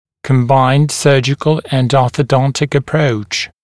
[kəm’baɪnd ‘sɜːʤɪkl ənd ˌɔːθə’dɔntɪk ə’prəuʧ][кэм’байнд ‘сё:джикл энд ˌо:сэ’донтик э’проуч]комбинированный хирургический и ортодонтический подход, комплексный хирургический и ортодонтический подход